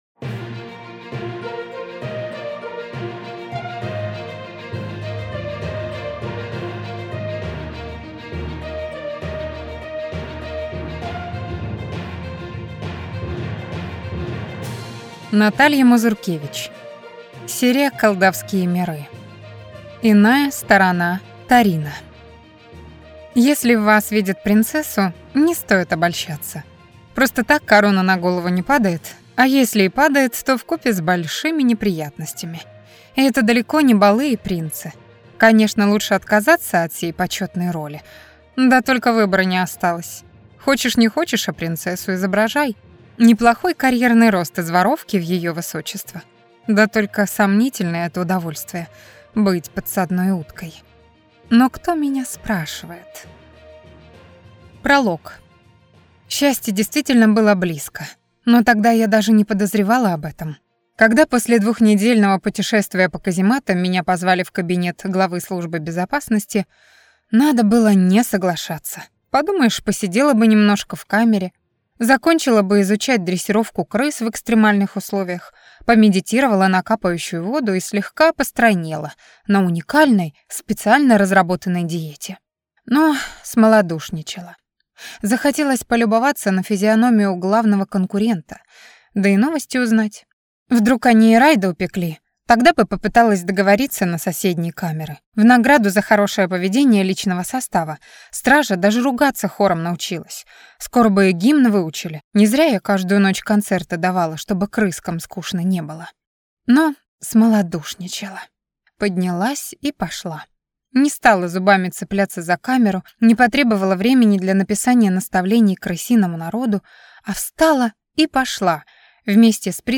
Аудиокнига Иная сторона Тарина | Библиотека аудиокниг